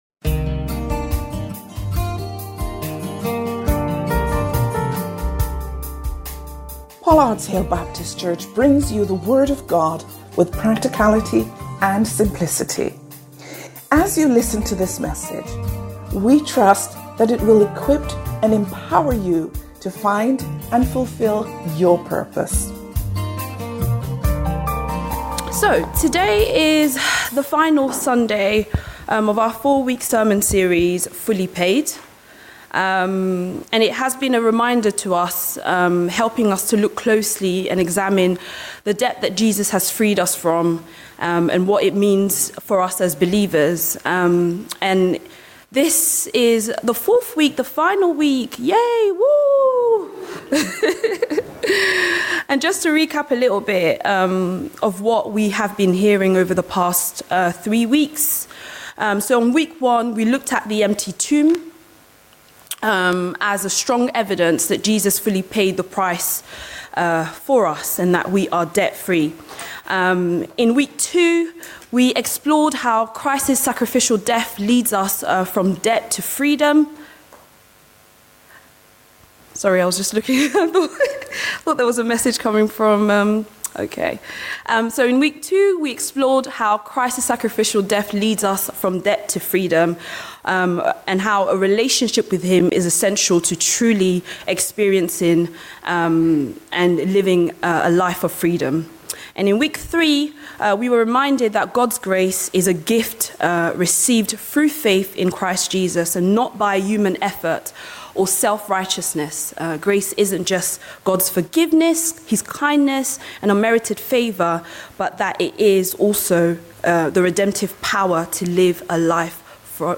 Life would be very different if all of our bills were marked as fully paid and we owed nothing to anyone. In this sermon series, we recognise that as Christians, our account with God does look like that, and we can live the abundant life because Jesus ensured that everything has been fully paid.